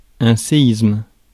Ääntäminen
Synonyymit tremblement de terre Ääntäminen France: IPA: [ɛ̃ se.izm] Tuntematon aksentti: IPA: /se.ism/ Haettu sana löytyi näillä lähdekielillä: ranska Käännös Ääninäyte Substantiivit 1. quake 2. earthquake USA Suku: m .